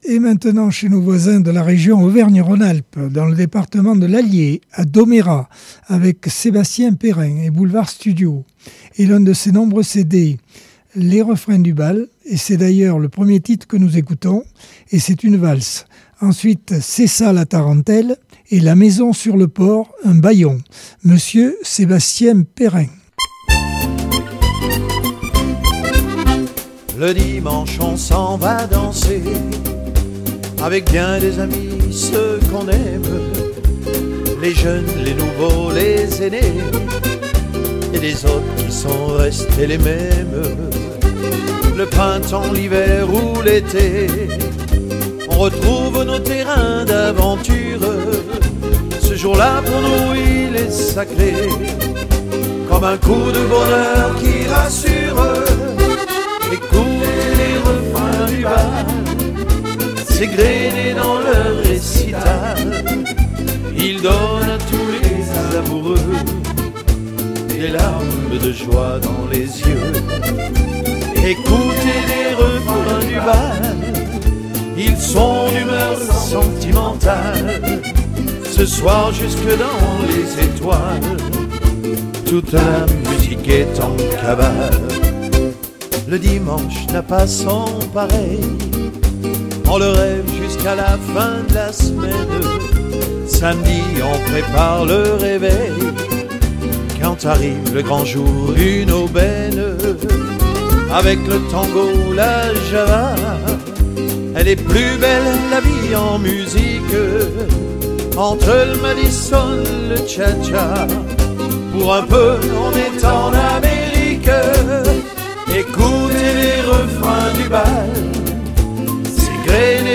Accordeon 2025 sem 06 bloc 2 - Radio ACX